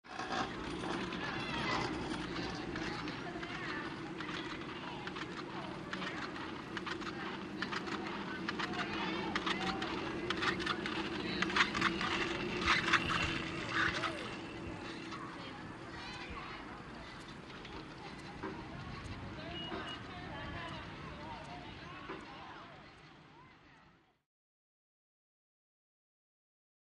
Ski Lift; Chairlift Ambience With Walla, Creaky Mechanism Noise.